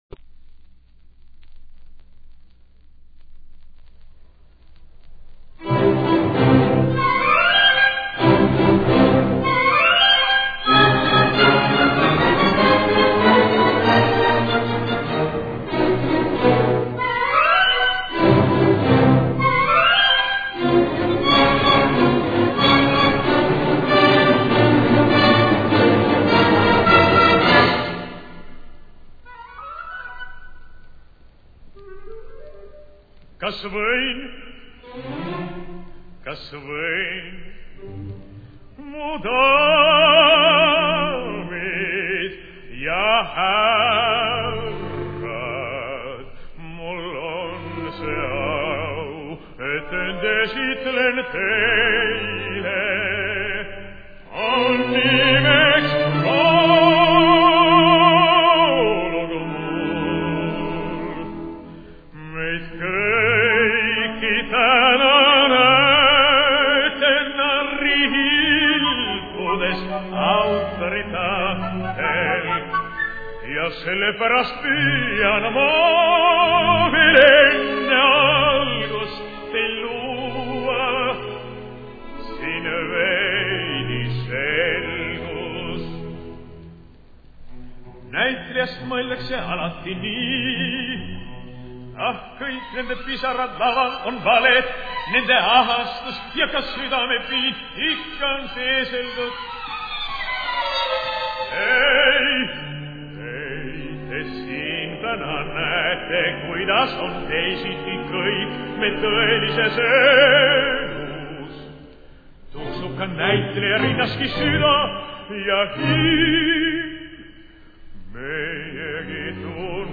Estonian baritone, 1920 - 1975